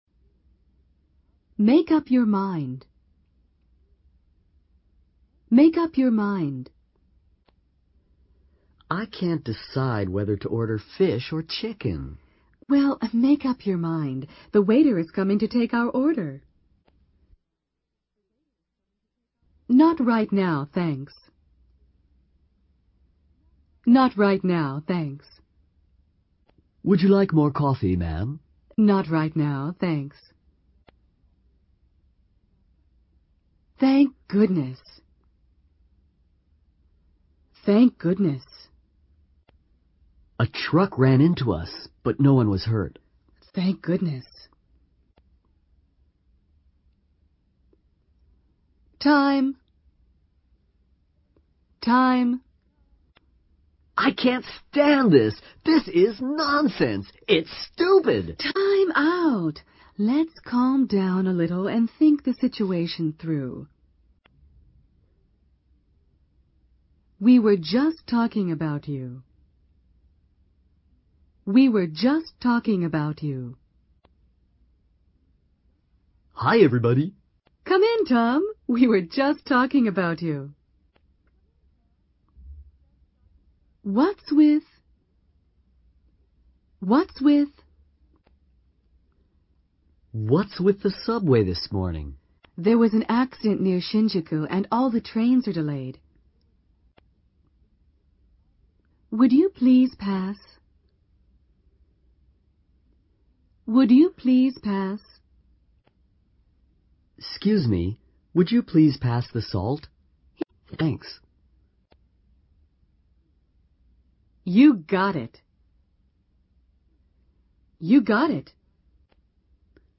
在线英语听力室英语口语常用短句 第13期的听力文件下载,《英语口语常用短句》包含了纯正的英语发音以及常用的英语口语短句，并附有中英字幕文件LRC，是学习初级英语口语，提高英语口语水平的必备英语材料。